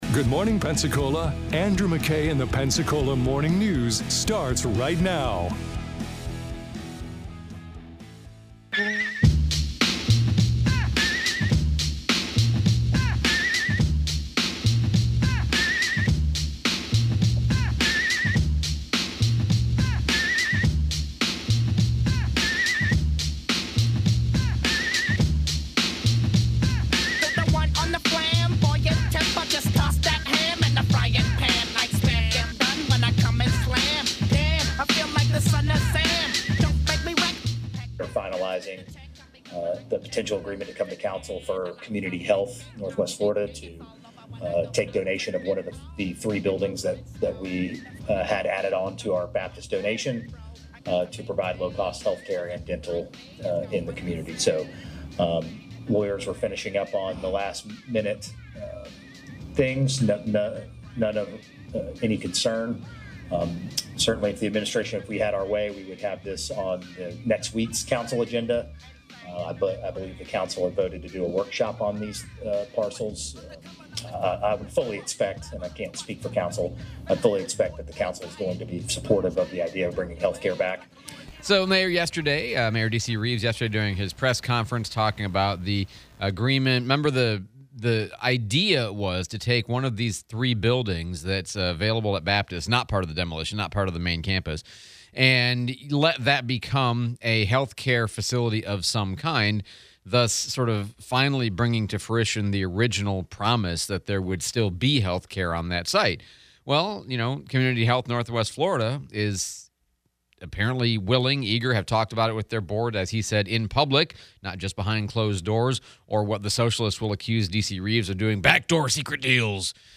Mayor DC Reeves press conference, Replay of Escambia County Sheriff Chip Simmons